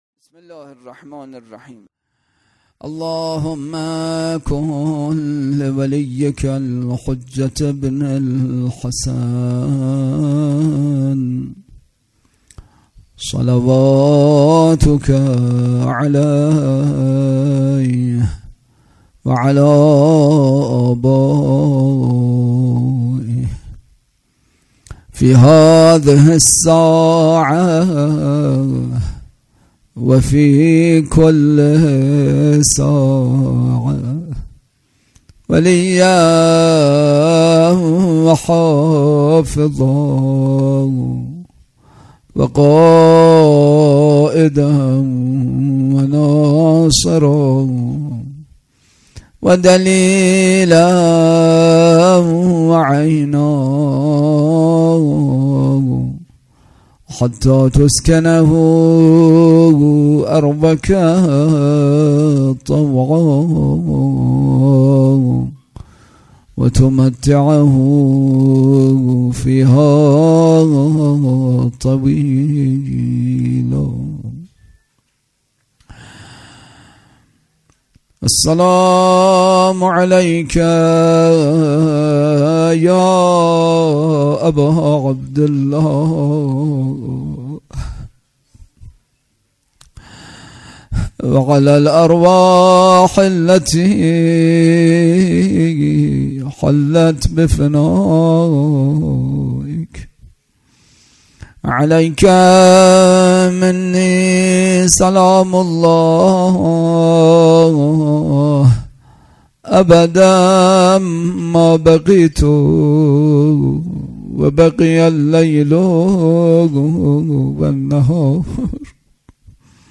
سخنرانی
برگزارکننده: مسجد اعظم قلهک